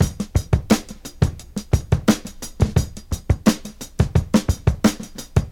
87 Bpm Drum Beat E Key.wav
Free drum loop - kick tuned to the E note. Loudest frequency: 1226Hz
87-bpm-drum-beat-e-key-ytk.ogg